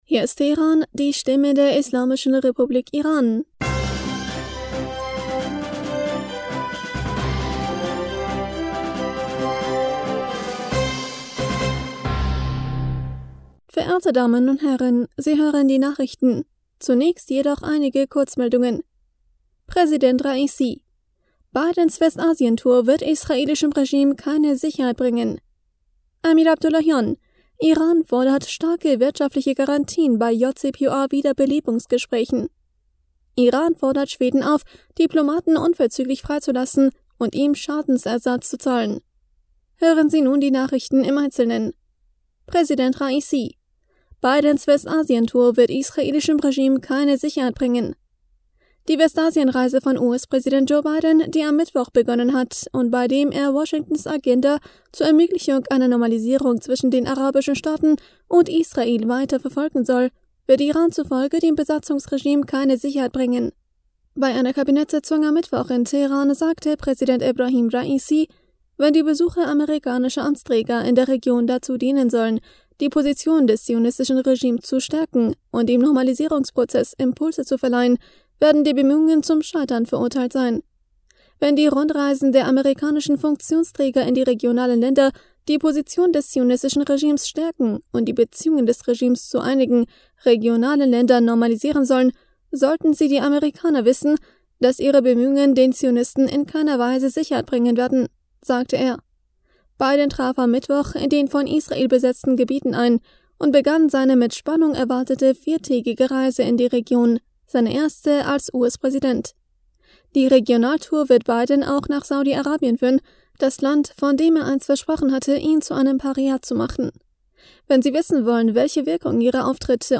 Die Nachrichten von Donnerstag, dem 14. Juli 2022